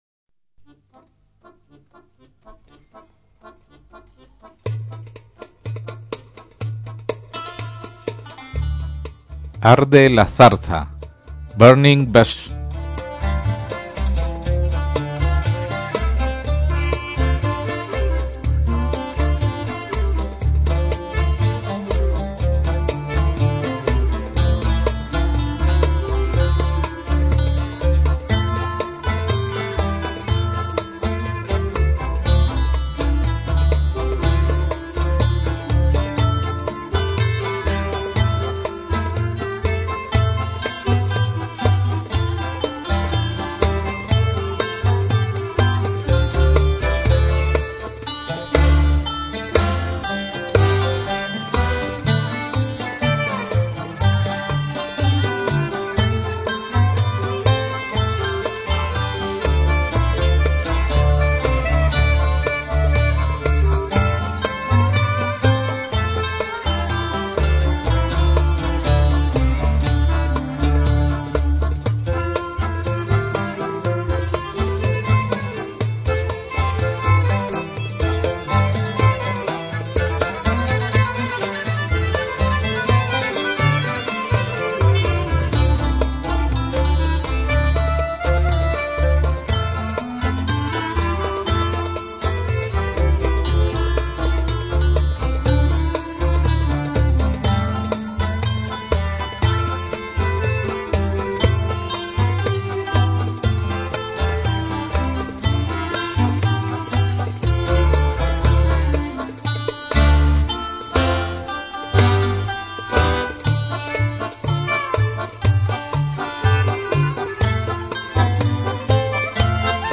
voz
clarinete
violín
acordeón, qanun y santur
oud, guitarra y percusiones
contrabajo